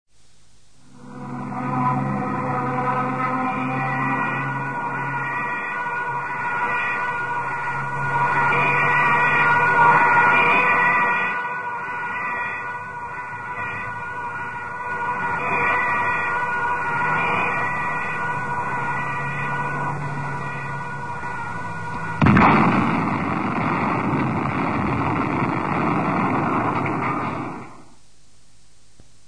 Звуки самолетов
Пикирующий боевой самолет с грохотом взрыва во Второй мировой войне